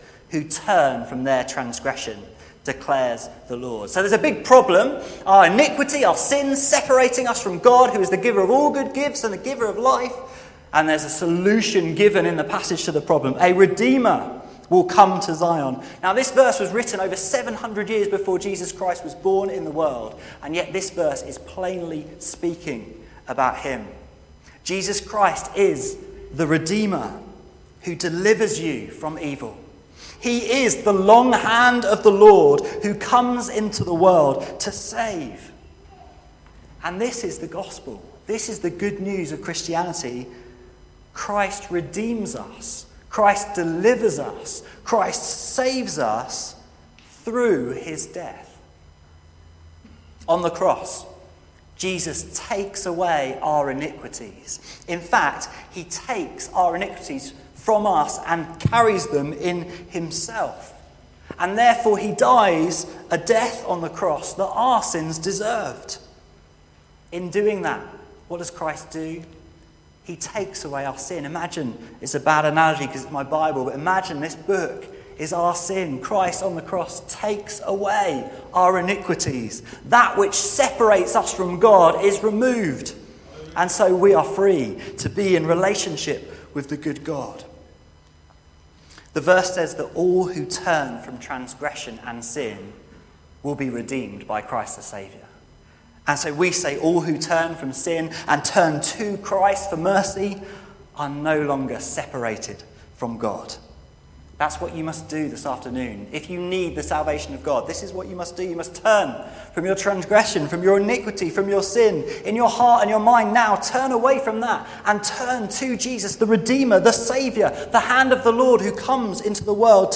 This sermon proclaims the truth in these verses that God Saves.